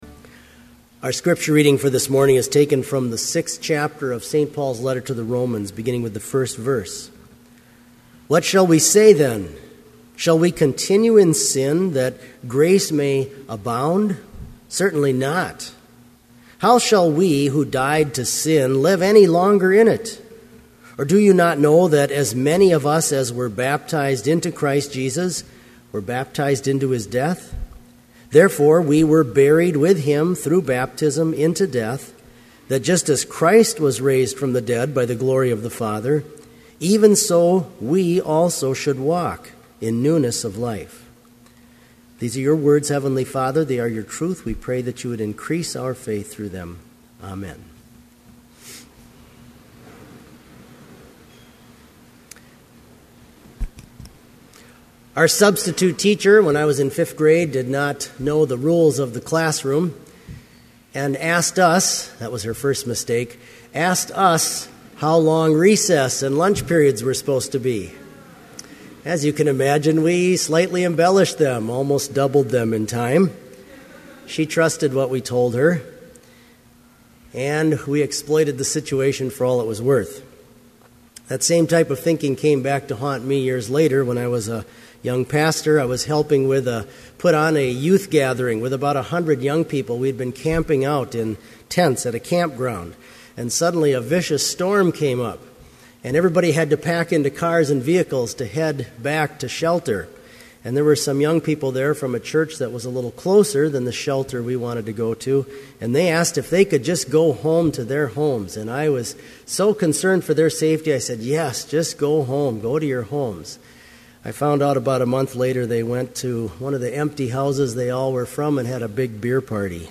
Complete service audio for Chapel - February 27, 2012